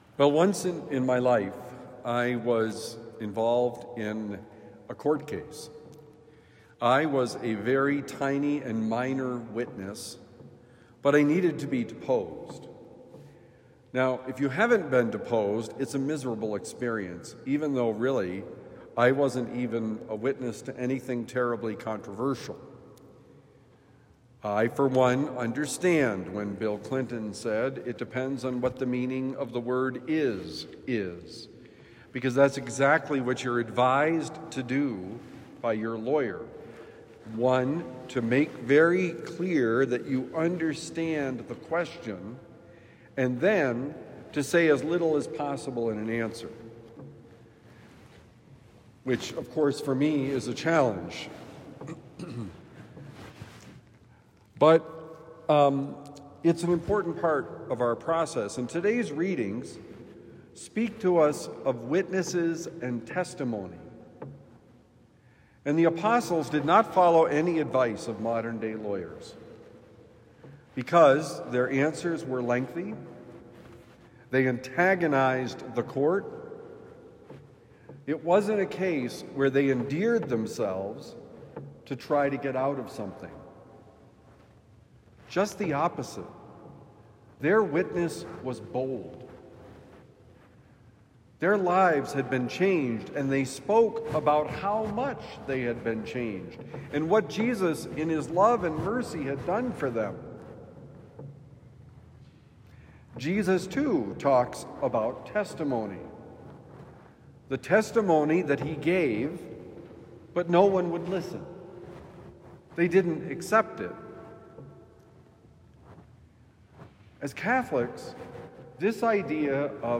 Share the Good News: Homily for Thursday, April 16, 2026